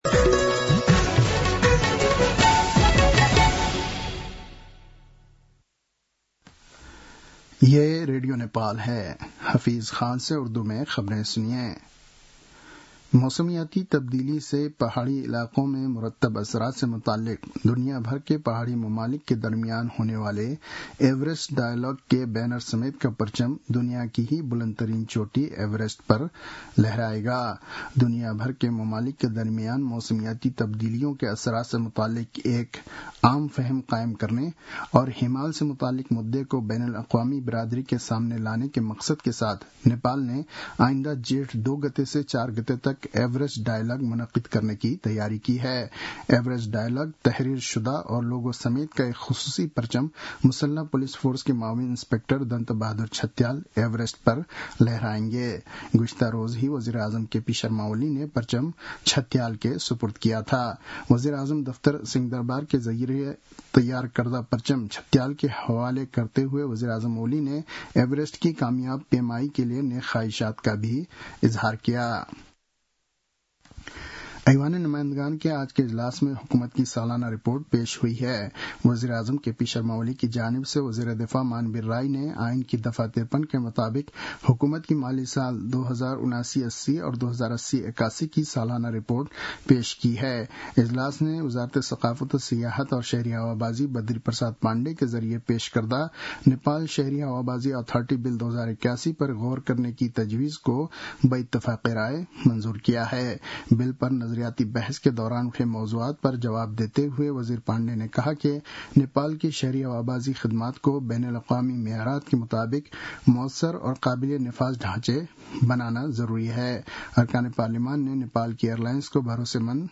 उर्दु भाषामा समाचार : १५ वैशाख , २०८२
Urdu-news-1-15.mp3